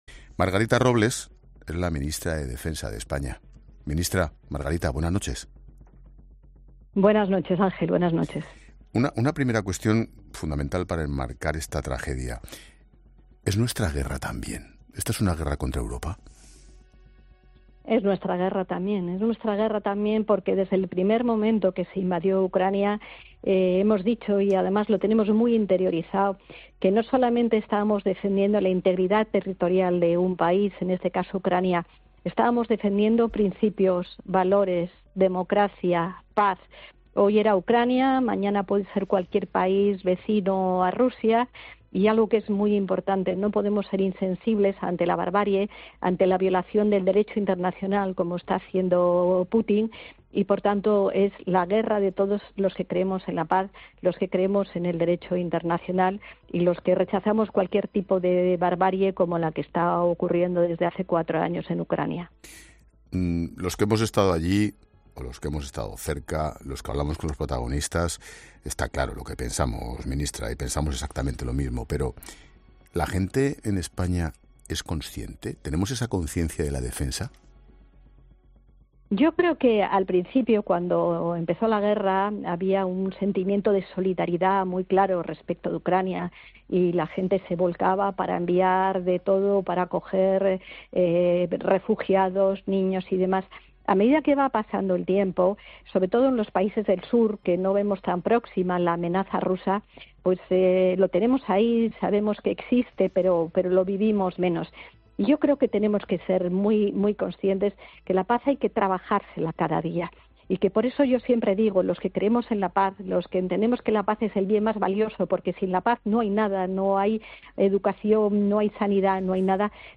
Expósito entrevista a Margarita Robles, ministra de Defensa